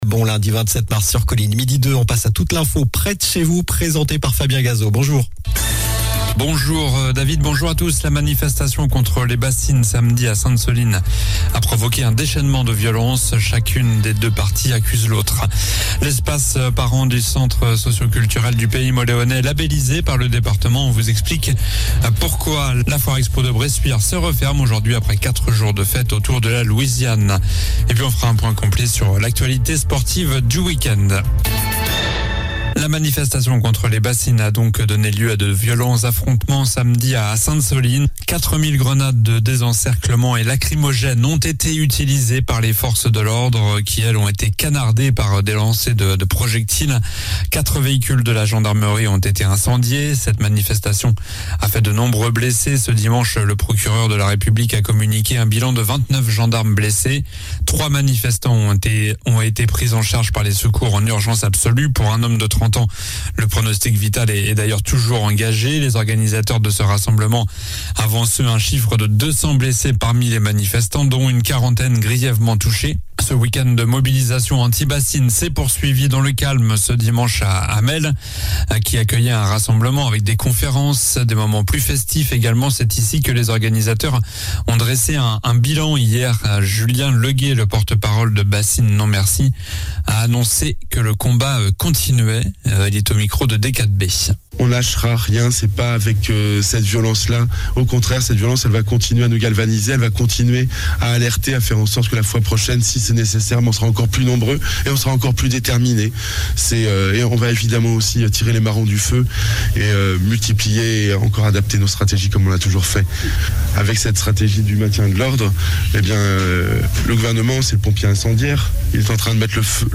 Journal du lundi 27 mars (midi)